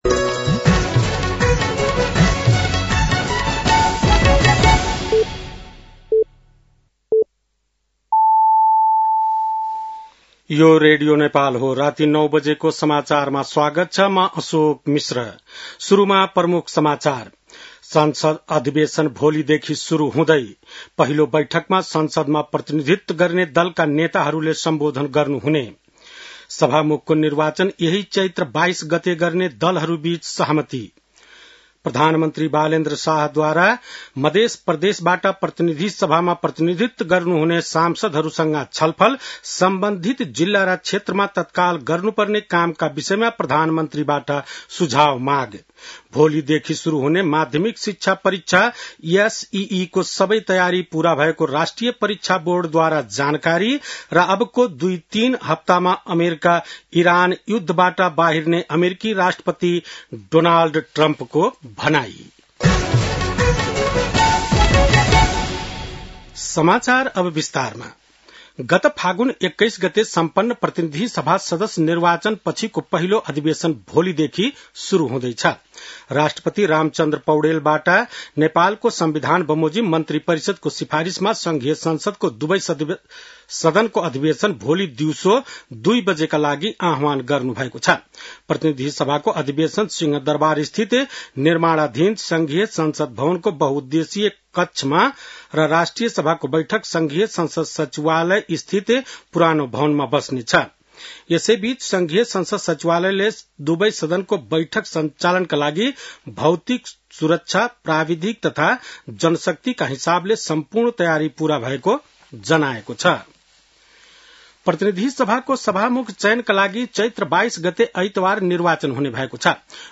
बेलुकी ९ बजेको नेपाली समाचार : १८ चैत , २०८२